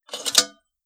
Sell Object.wav